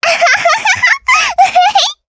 Taily-Vox_Happy3_jp.wav